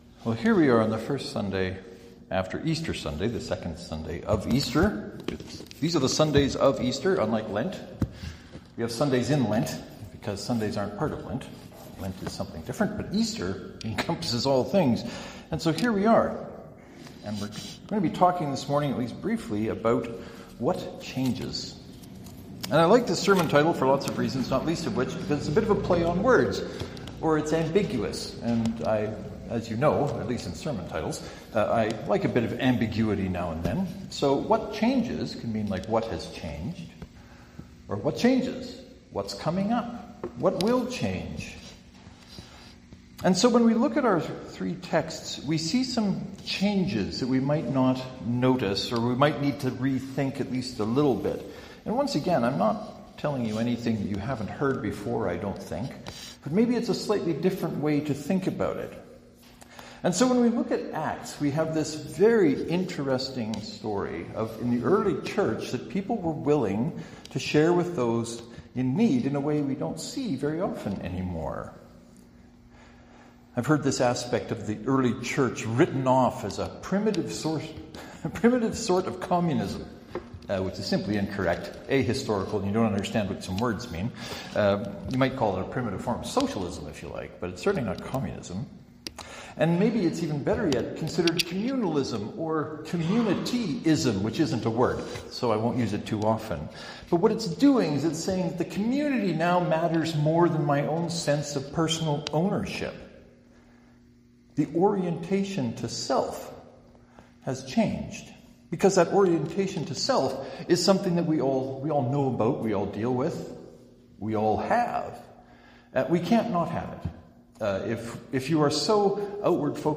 I hope you find value in the sermon and if you feel so inclined and able, let me know what you think.